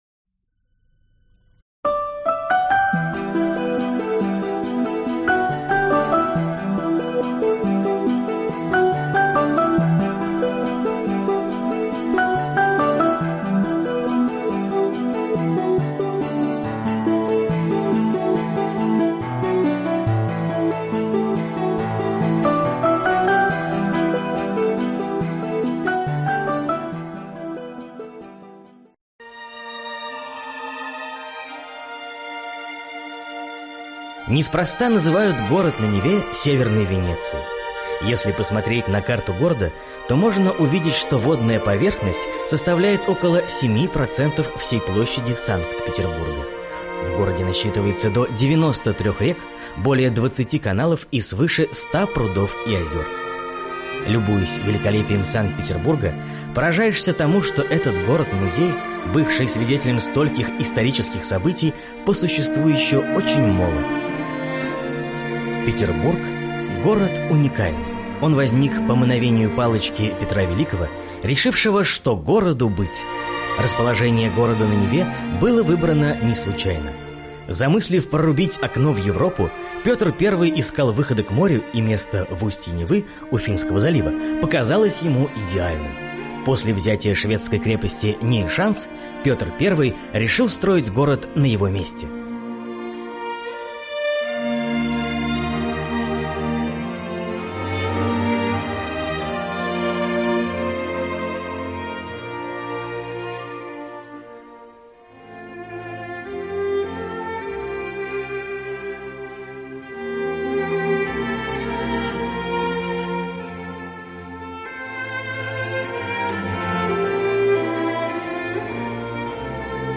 Аудиокнига Санкт-Петербург – Северная Венеция. Путеводитель | Библиотека аудиокниг